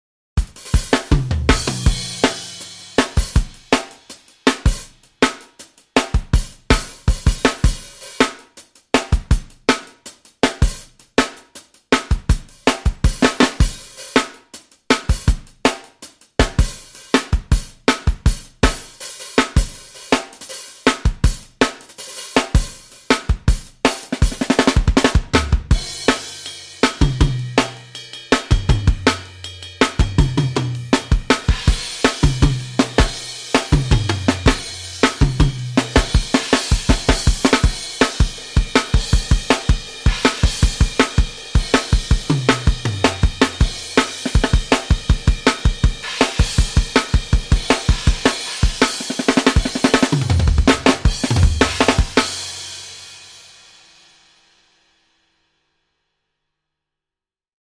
六 个原声鼓套件和超过 400 种经典鼓机声音
在英国伦敦的英国格罗夫工作室拍摄
为了增加更加有机和正确的时期风味，所有鼓和机器在数字转换之前都被记录到磁带上。
热门制作人 SDX -雷鬼摇滚套件